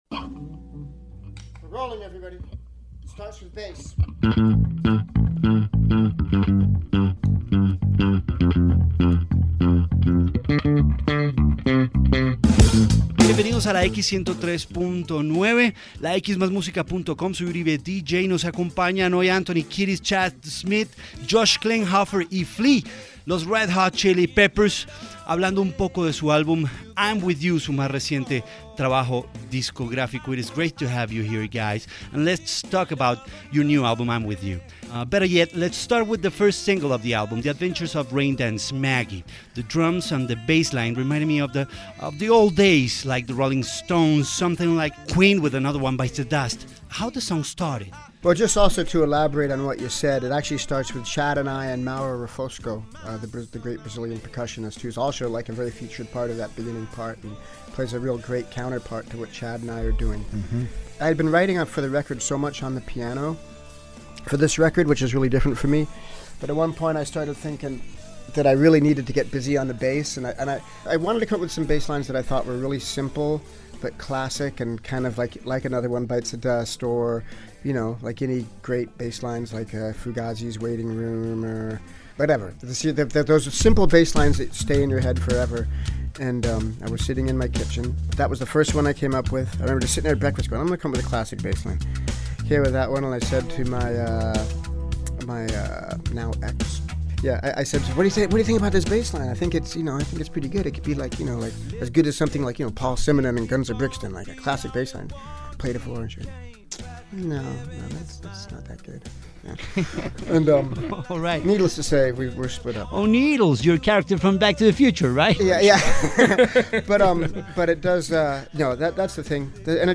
Entrevista-RHCP.mp3